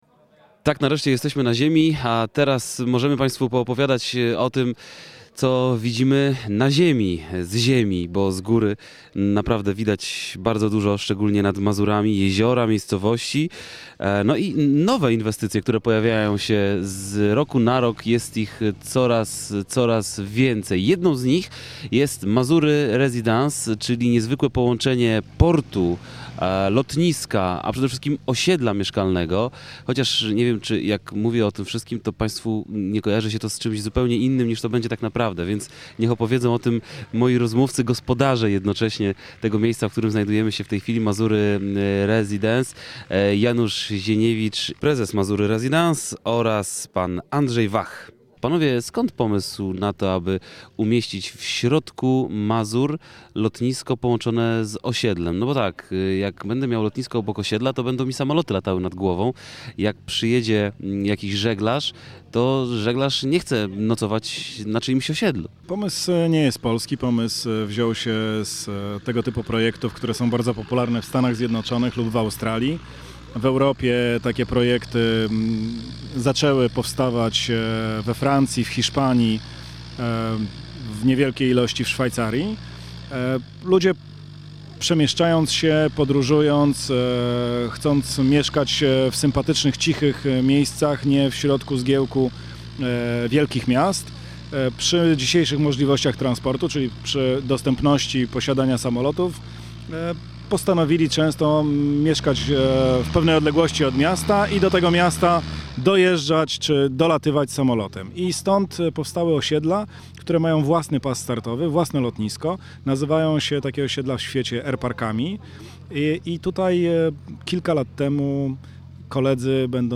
2009-06-13Relacja z Rajdu po Lotniskach i Lądowiskach Warmii i Mazur - j. Niegocin, cz. 1 (źródło: Radio Olsztyn)